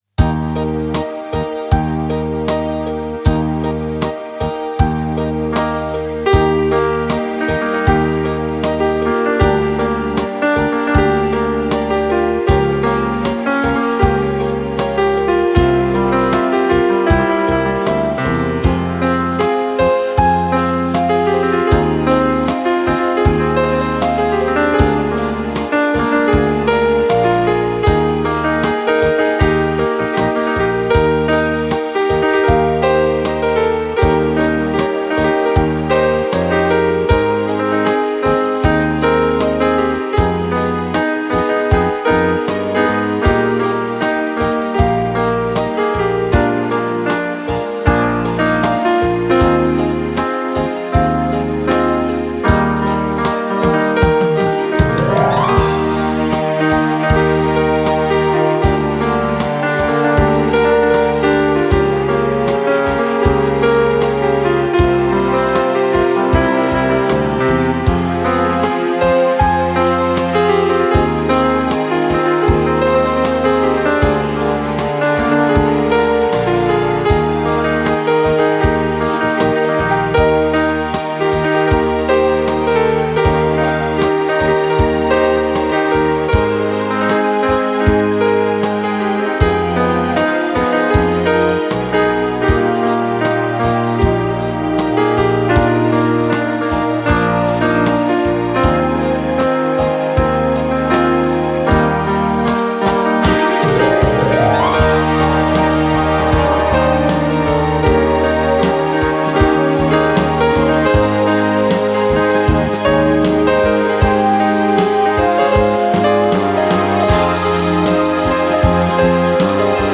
(INGEN former for overspil/trickindspilninger.)